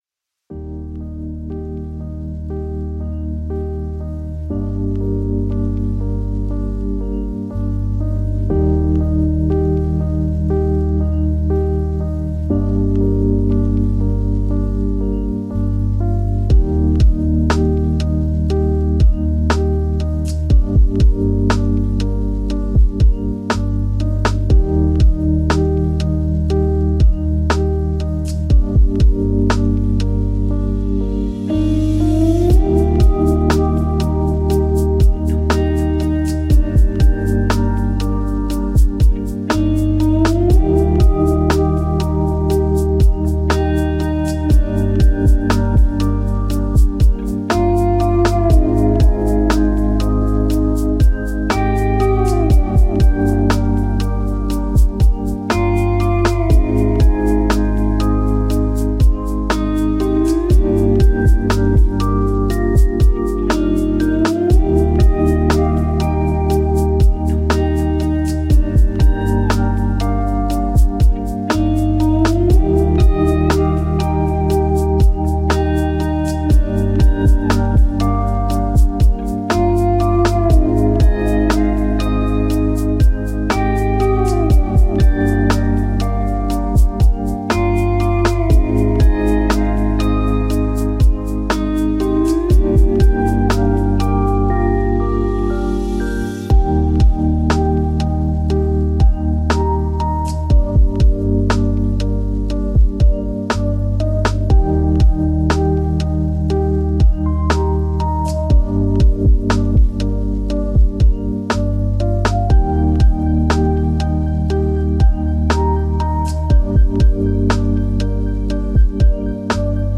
轻松愉悦